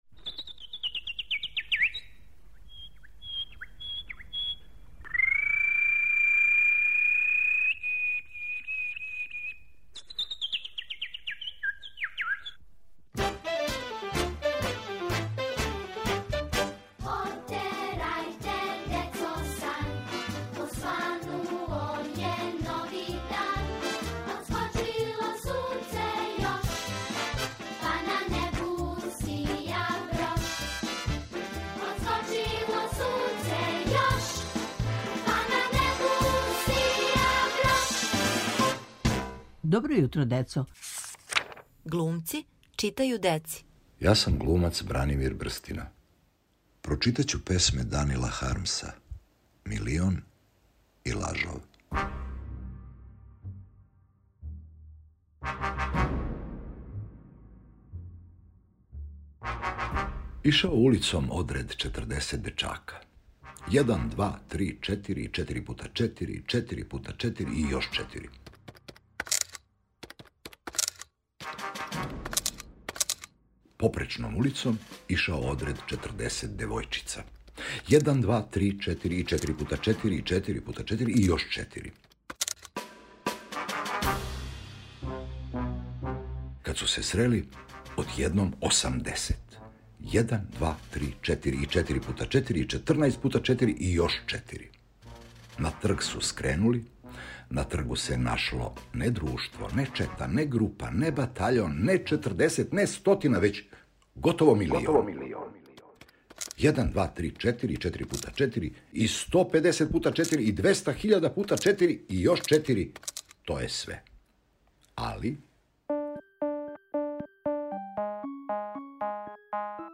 Зове се "Глумци читају деци". Глумац Бранимир Брстина ће вам, из своје куће, прочитати две песме Данила Хармса.